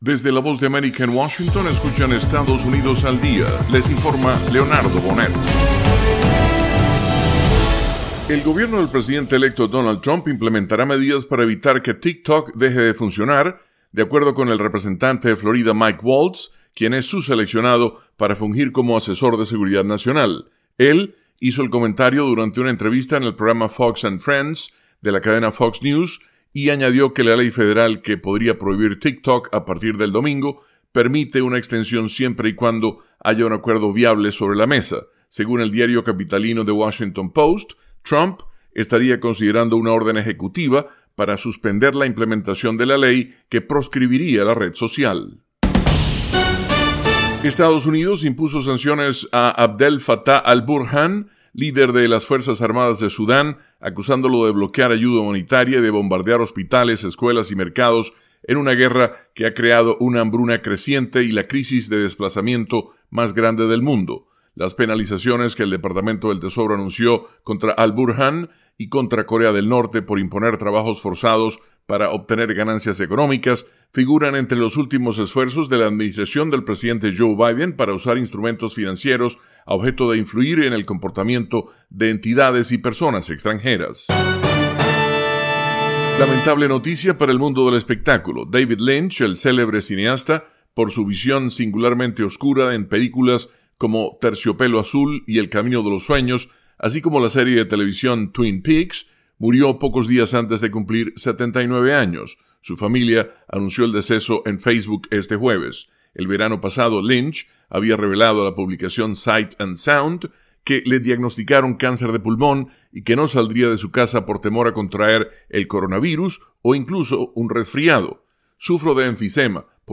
Estados Unidos al Día: Con algunas de las noticias nacionales más importantes de las últimas 24 horas.